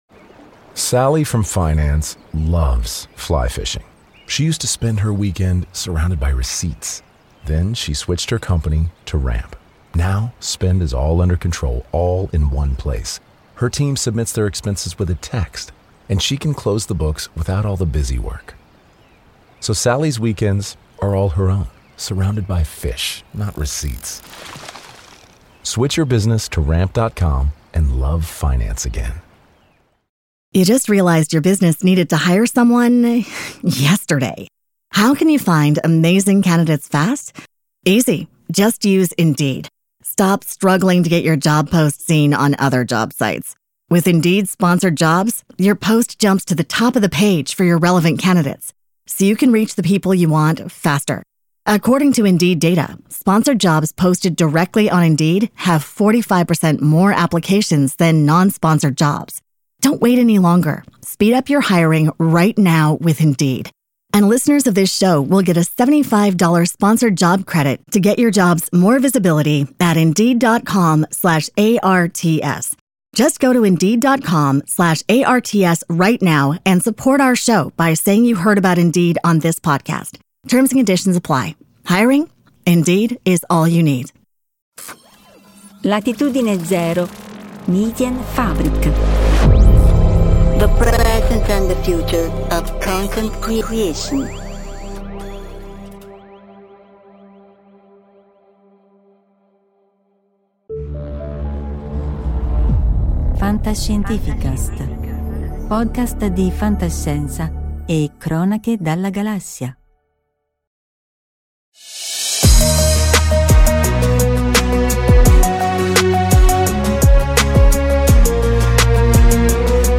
Incontro con Bob O'Reilly - Deepcon 23 - FantascientifiCast - Podcast